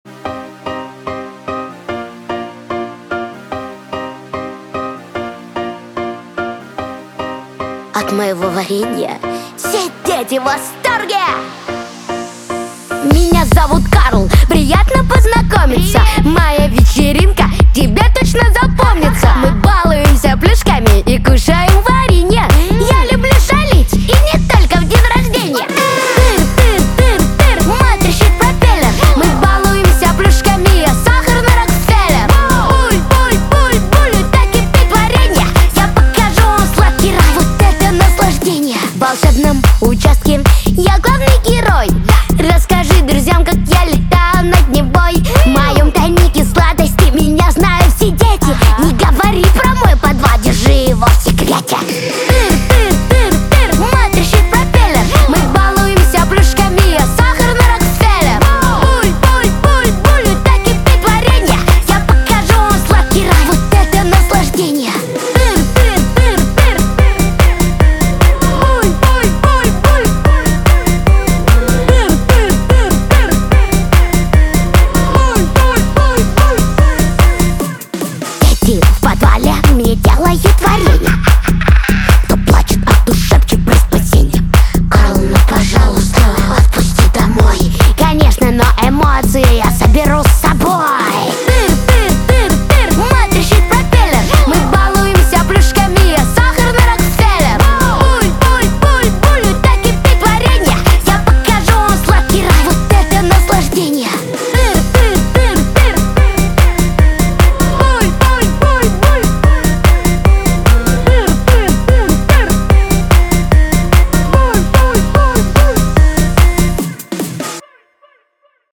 Качество: 320 kbps, stereo
Саундтреки